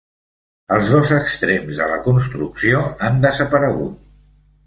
[ˈan]